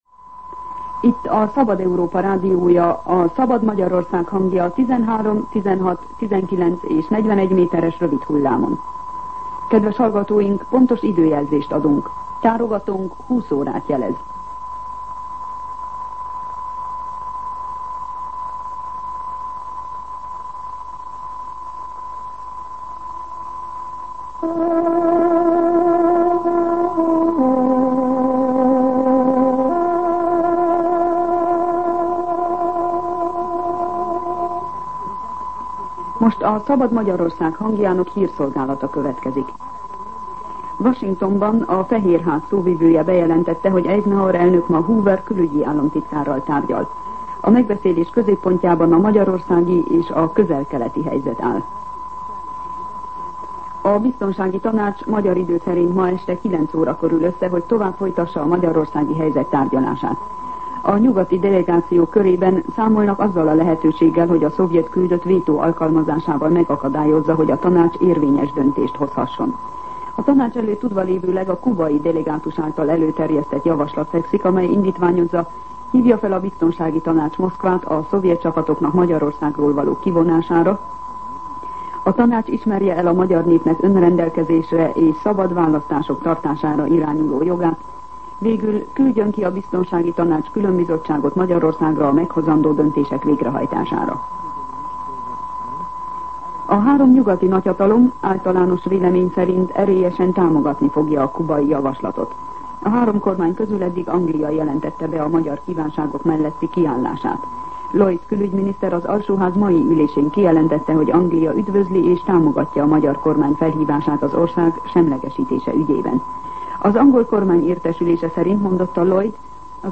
20:00 óra. Hírszolgálat